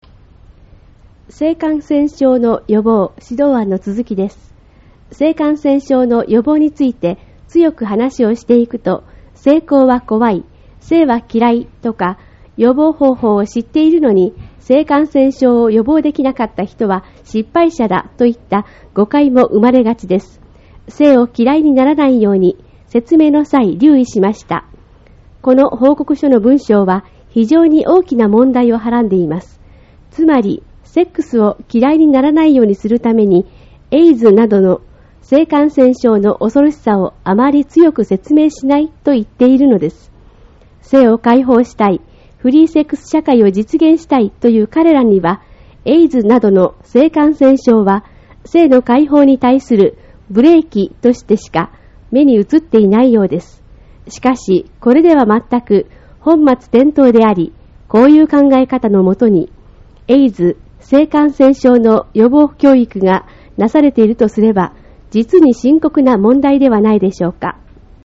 音声による説明 「性感染症の予防」指導案の続きです。